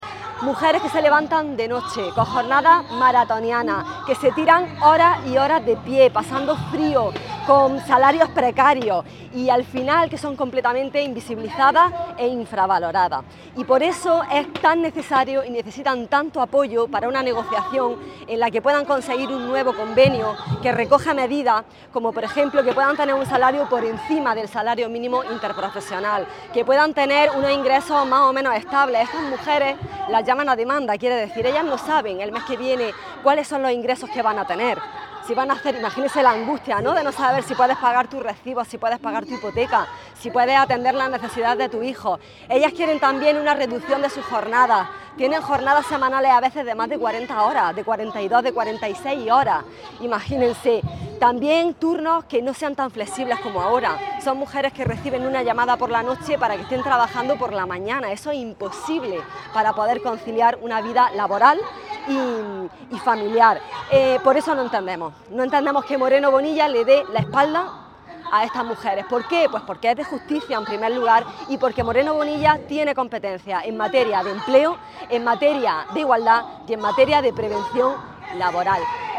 ha participado junto a otros representantes socialistas en la manifestación convocada por UGT y CCOO ante el bloqueo del convenio del manipulado hortofrutícola